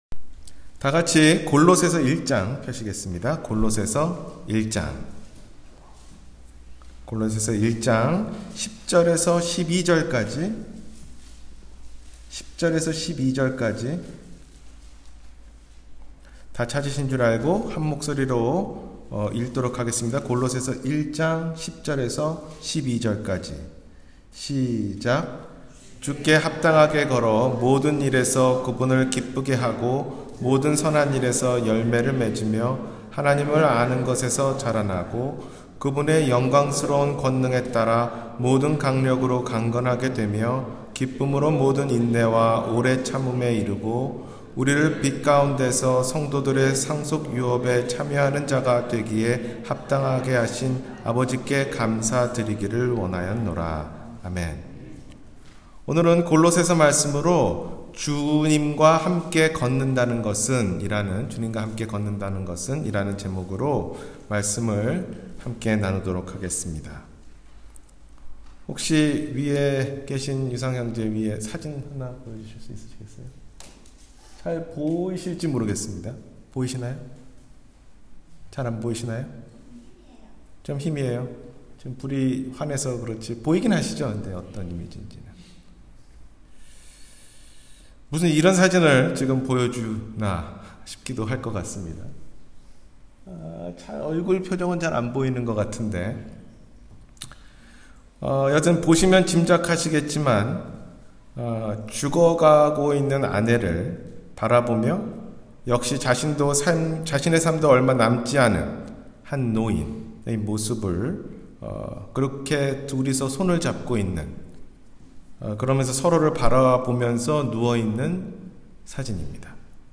주님과 함께 걷는다는 것은 – 주일설교